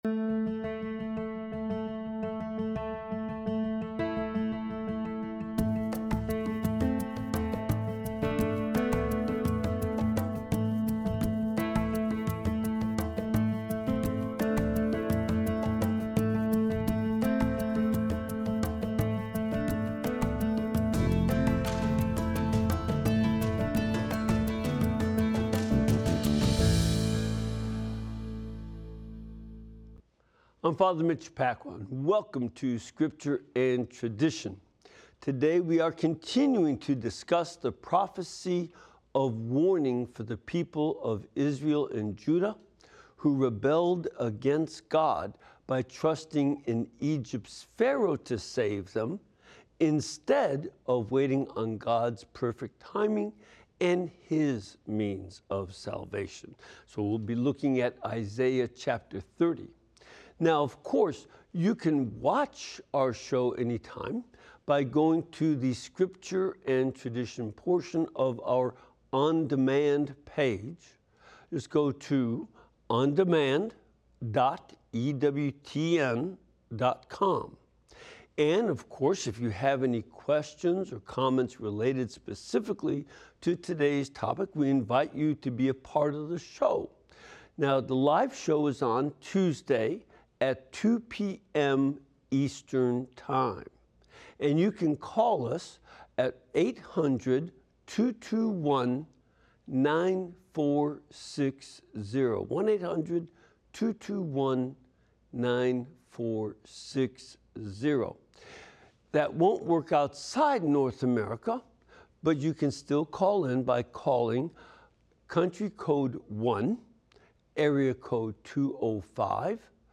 EWTN Favorite and Scripture Scholar Fr. Mitch Pacwa analyzes Church Traditions and Teachings in light of Sacred Scripture during this weekly live program.